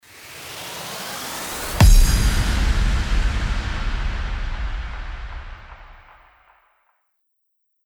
FX-1454-RISING-IMPACT
FX-1454-RISING-IMPACT.mp3